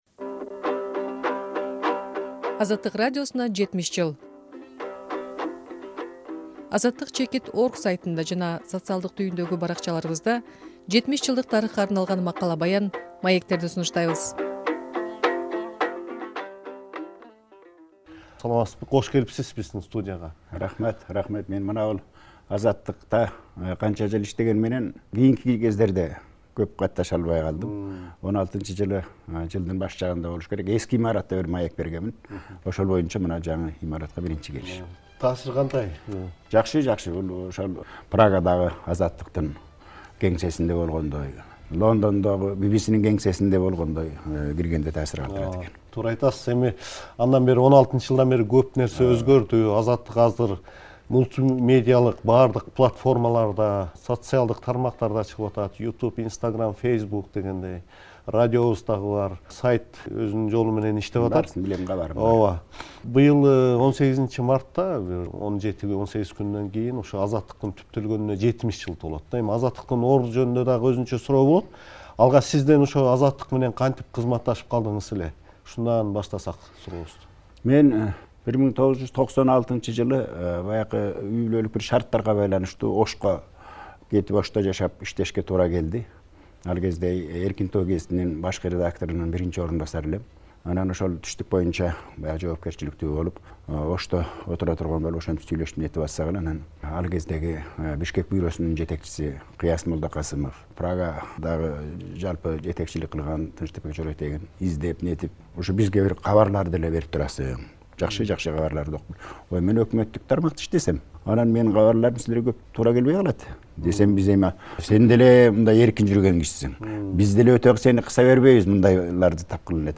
Аны менен радиодогу ишмердиги тууралуу маектештик.